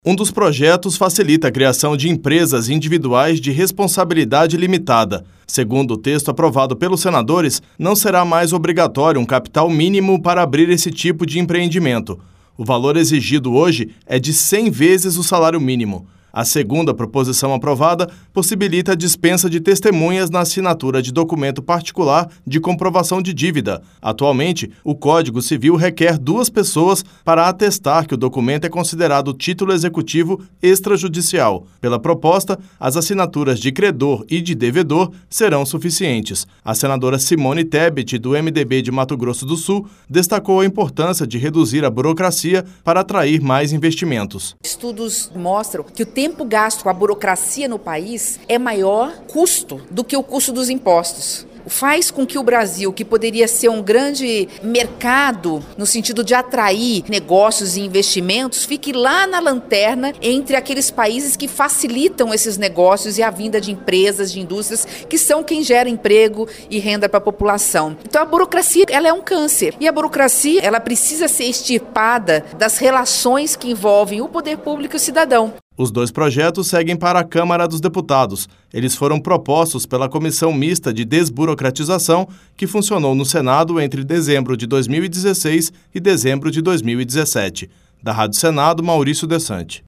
As informações na reportagem